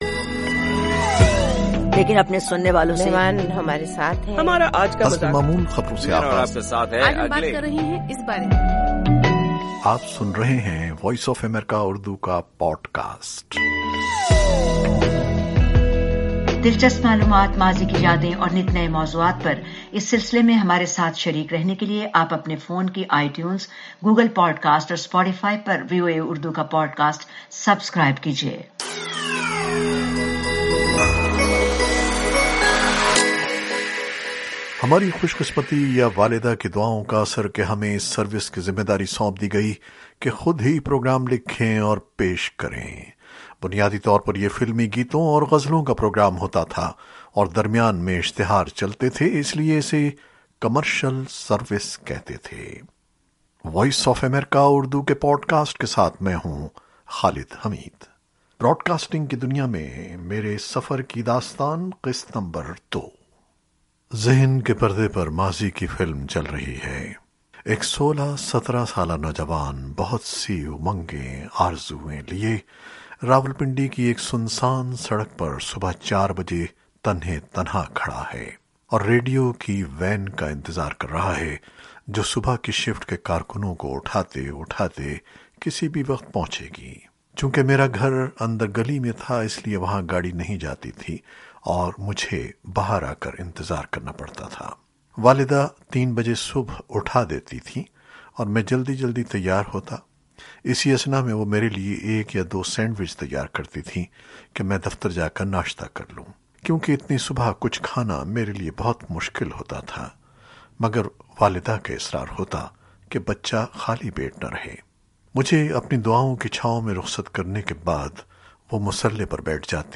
نشریات کی دنیا میں خالد حمید کن مراحل سے گزرے؟ سنتے ہیں انہیں کی زبانی ’سفر جاری ہے‘ کی دوسری قسط میں۔